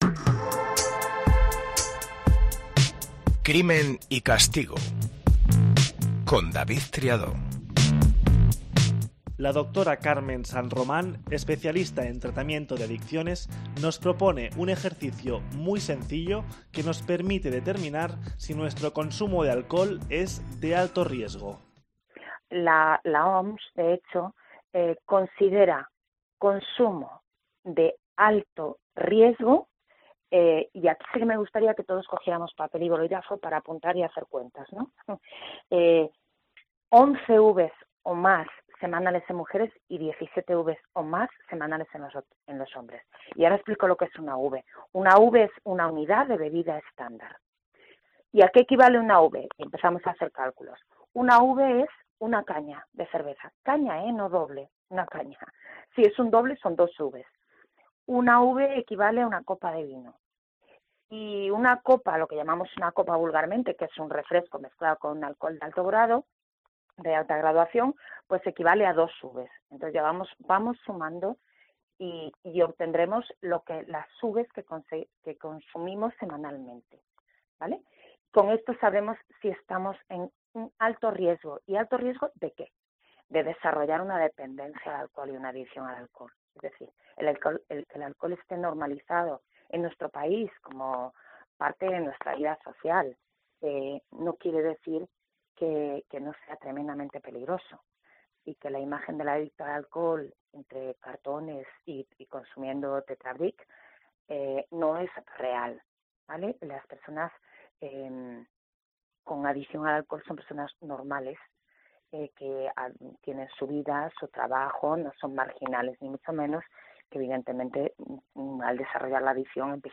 Lo hace en un formato dinámico, fresco, cercano, y con entrevistas a los implicados e intervenciones de expertos.